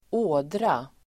Uttal: [²'å:dra]